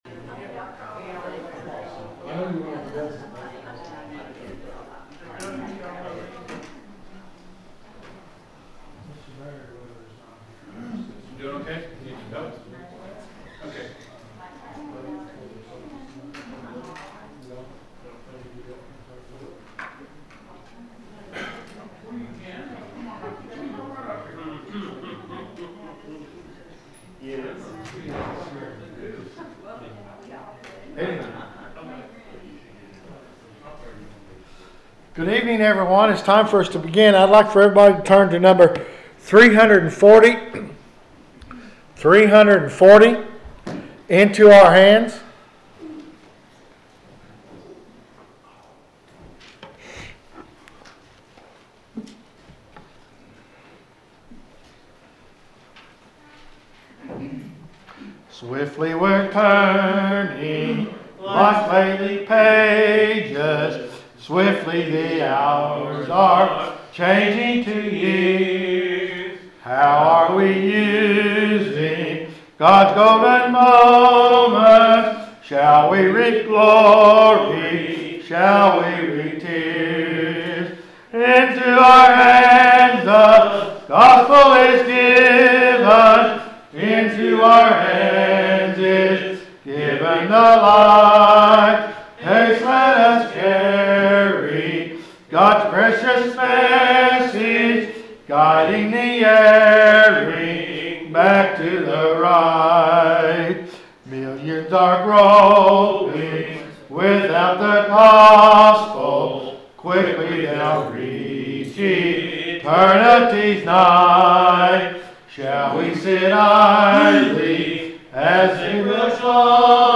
The sermon is from our live stream on 2/15/2026